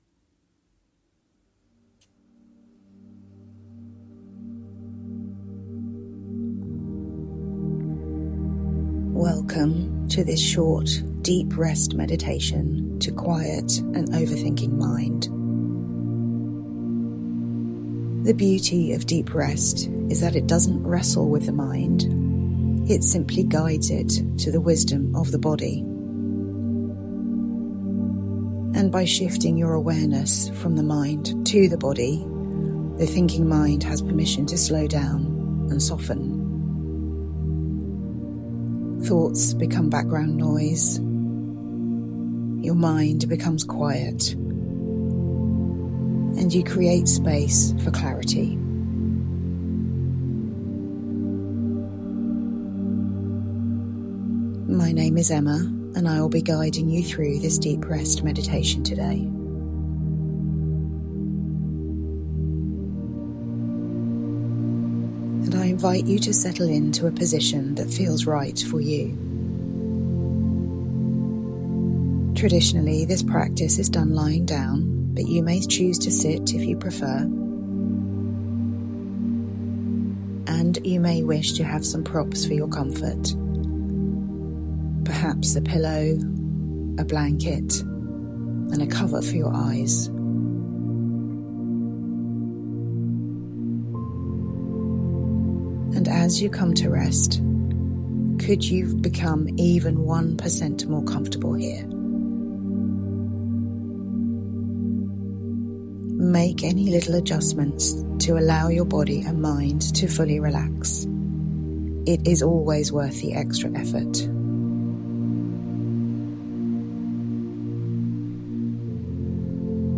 Deep Rest Meditation to quiet an overthinking mind